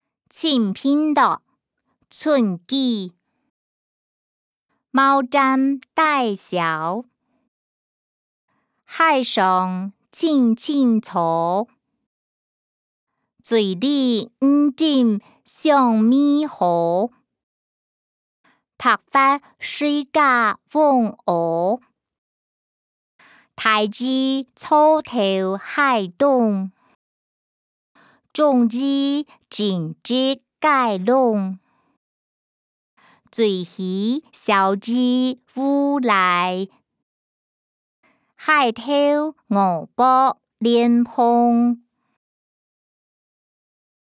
詞、曲-清平樂：村居 音檔(海陸腔)